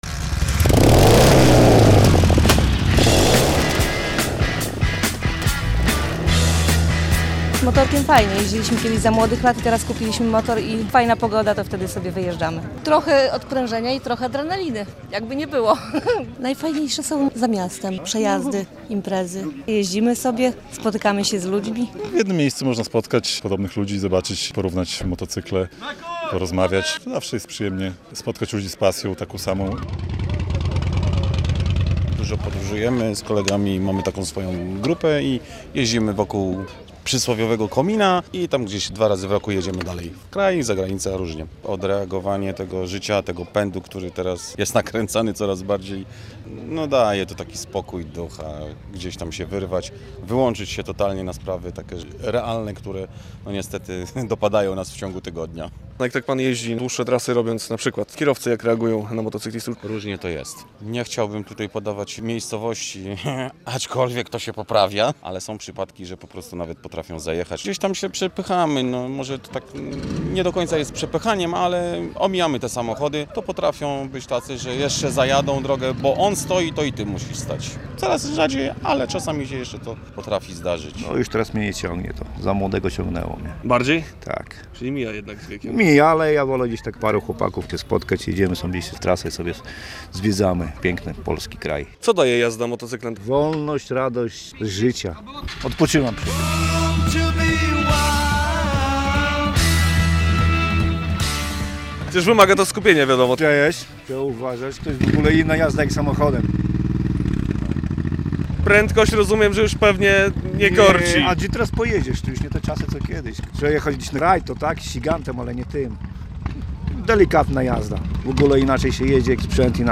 II Pielgrzymka Motocyklowa Polski Północno-Wschodniej, 14.05.2023, Łomża, fot.
Z motocyklistami, właścicielami zarówno maszyn zabytkowych, jak i  turystycznych rozmawiał